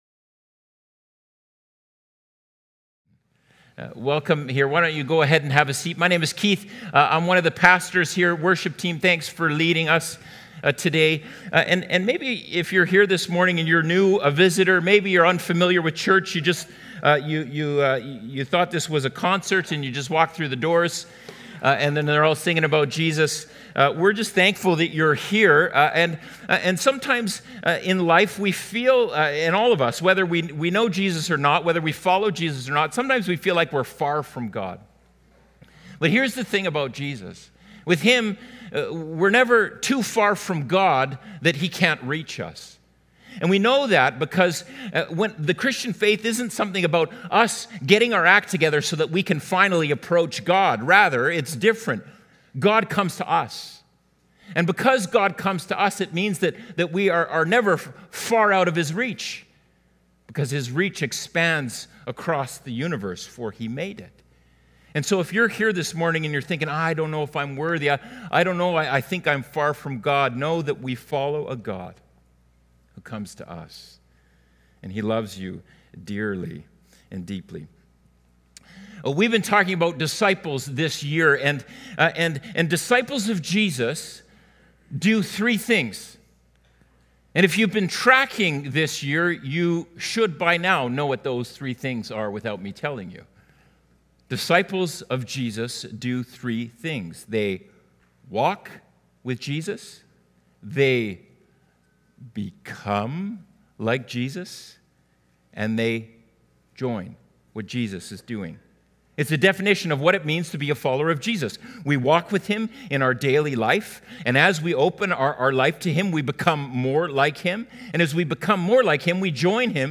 Sermons | Mission Creek Alliance Church